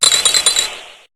Cri de Sorbébé dans Pokémon HOME.